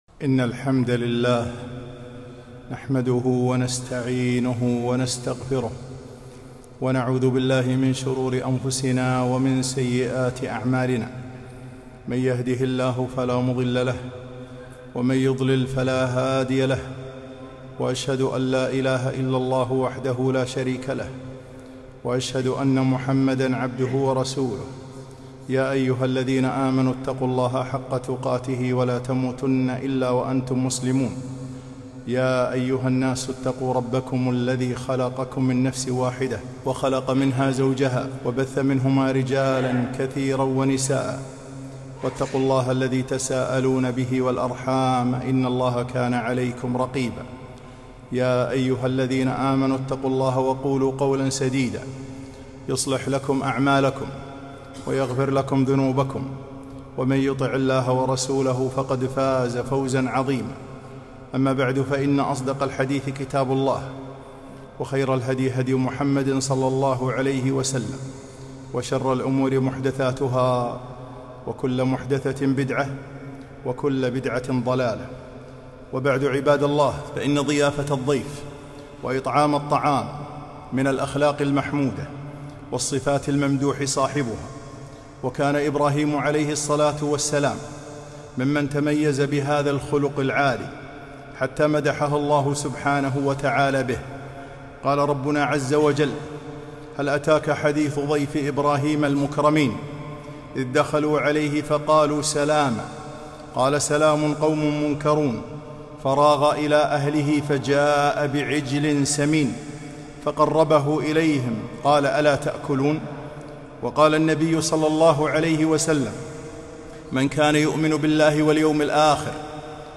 خطبة - بذل الطعام وذم البخلاء